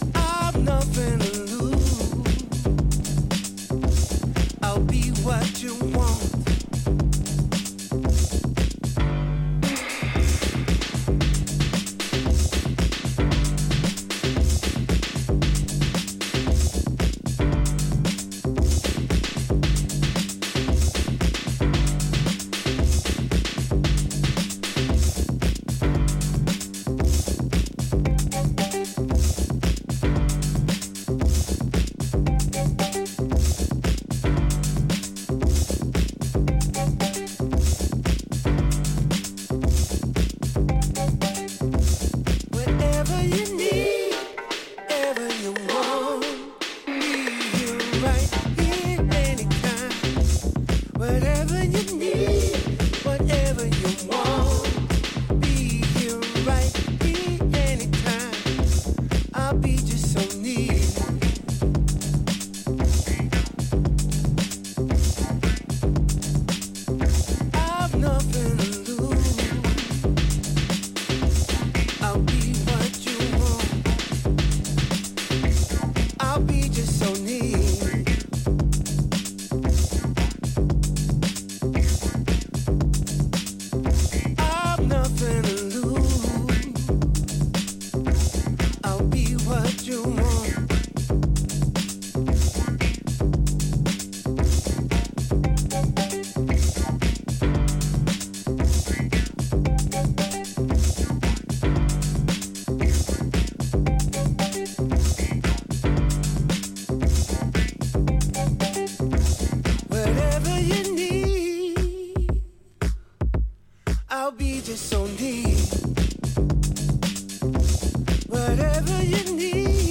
場面に応じて、よりBPMを抑えたB面の方も使えそうですね。
Vocal Remix